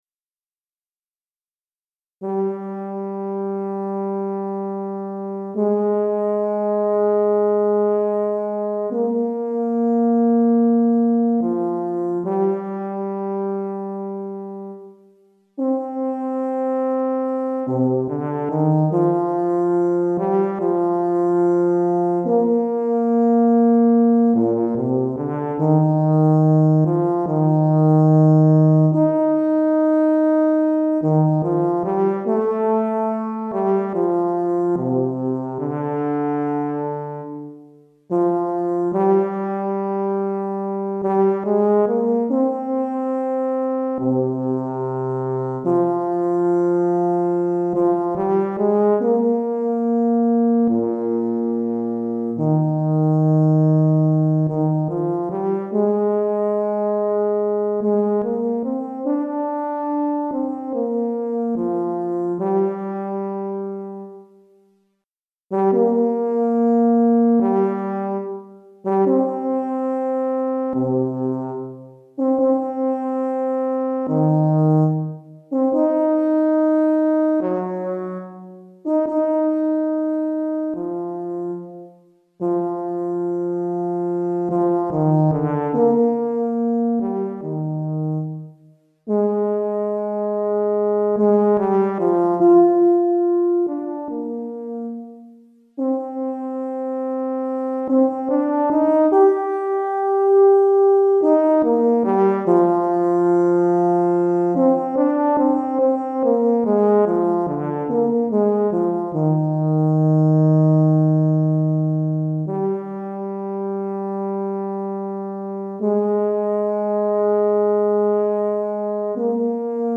Tuba Solo